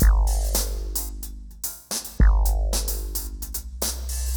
RemixedDrums_110BPM_32.wav